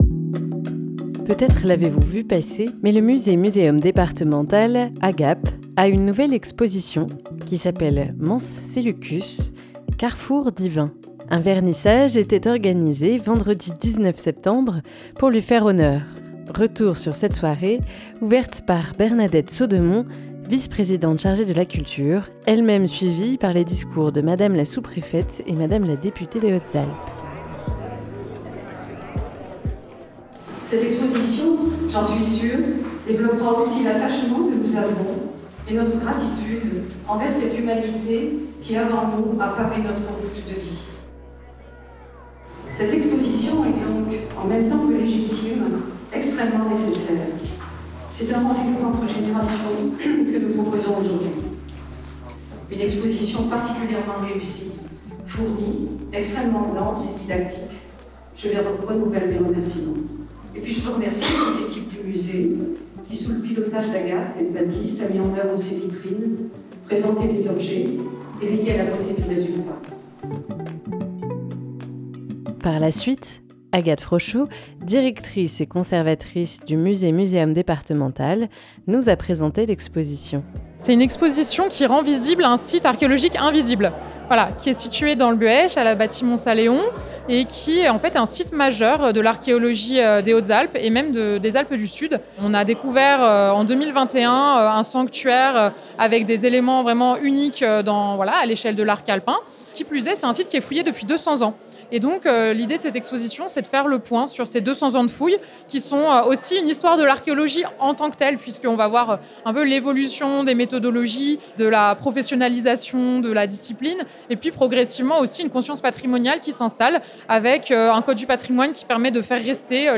Retour sur cette soirée, ouverte par Bernadette Saudemont , Vice-Présidente chargée de la Culture, elle même suivie par les discours d' Hélène Dargon , sous préfète, et Marie José Allemand, députée de la 2e circonscription des Hautes-Alpes.